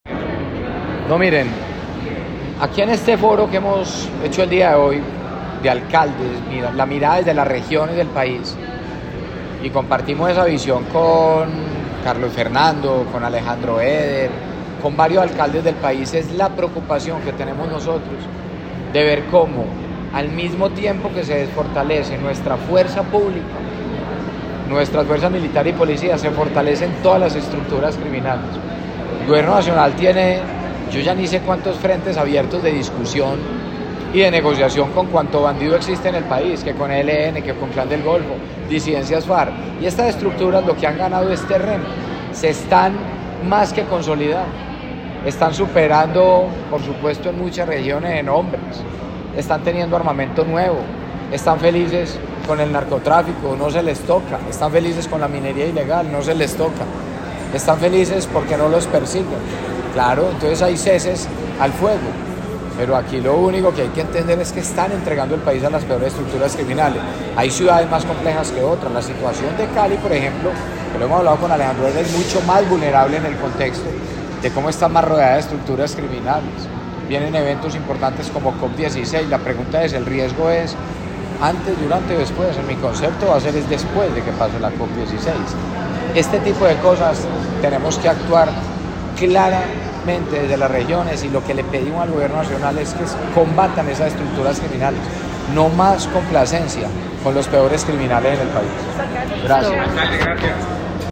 Palabras de Federico Gutiérrez Zuluaga, alcalde de Medellín